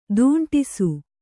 ♪ dūṇṭisu